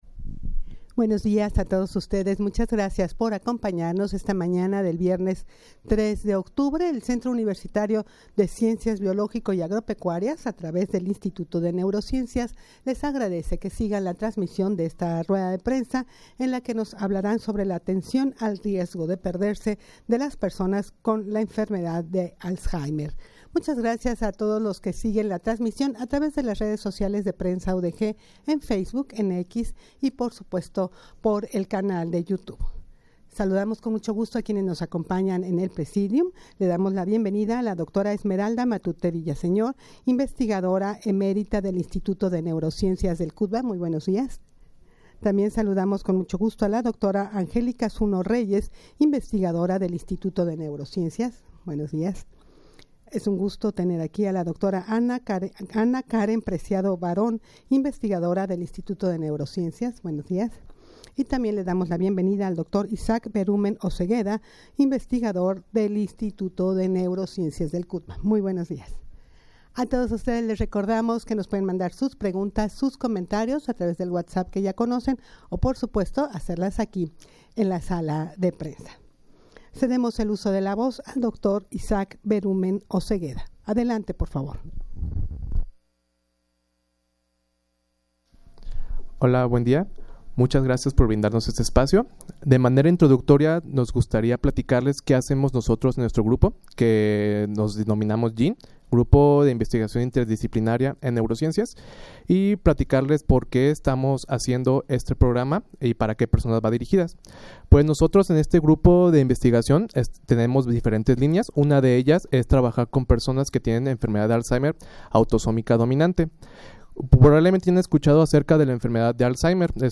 Audio de la Rueda de Prensa
rueda-de-prensa-atencion-al-riesgo-de-perderse-de-las-personas-con-enfermedad-de-alzheimer.mp3